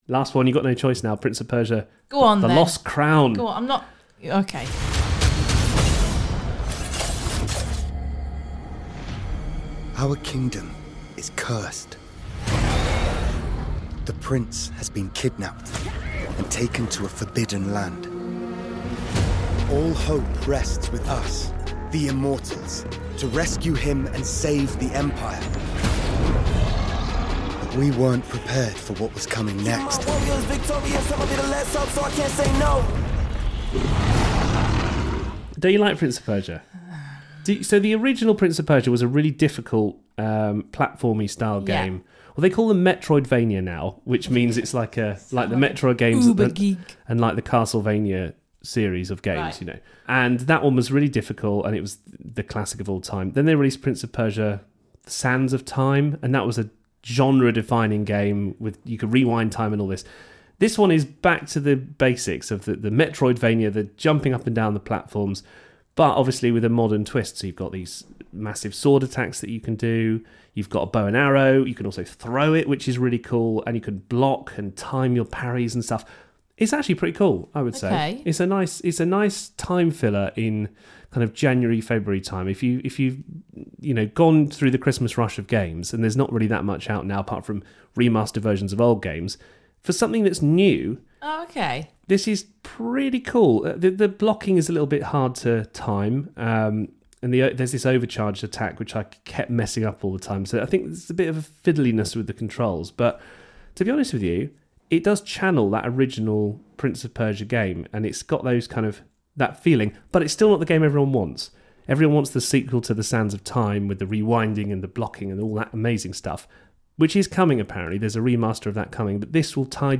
Review: Prince of Persia: The Lost Crown